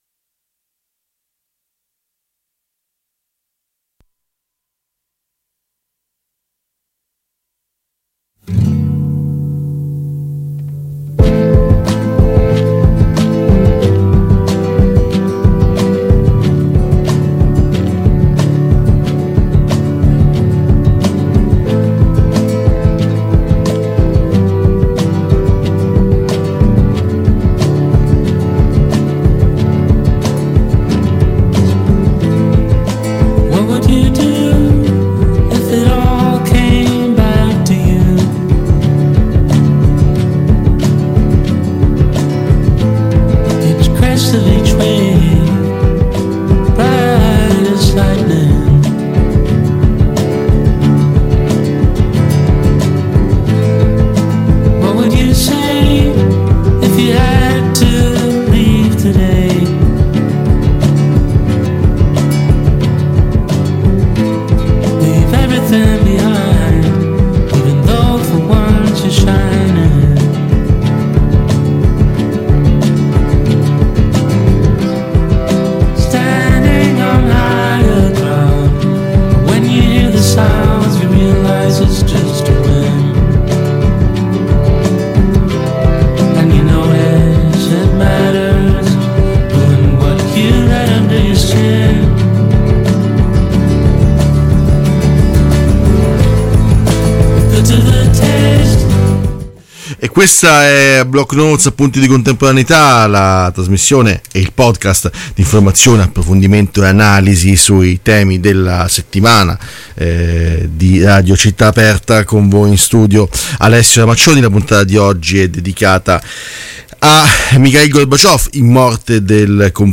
Partendo da queste parole, abbiamo provato ad analizzare la figura dell’ultimo segretario del Partito Comunista dell’Unione Sovietica. In studio